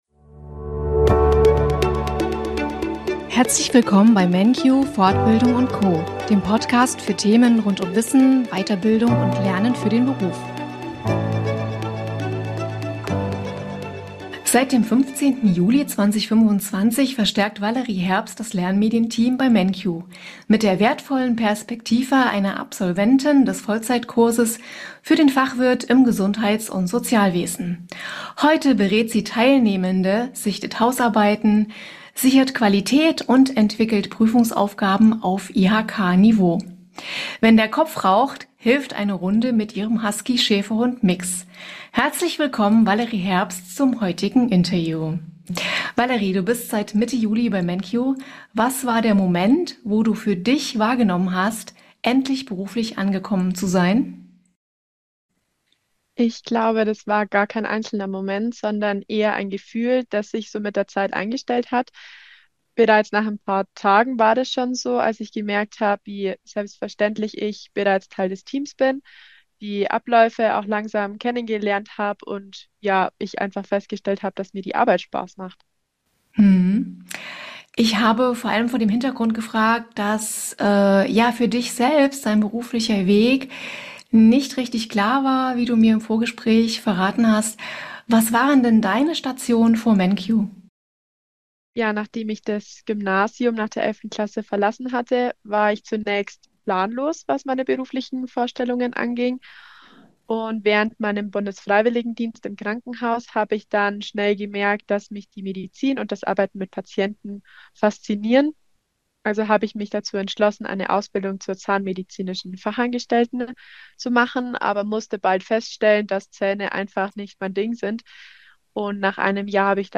Viel Spaß beim Interview!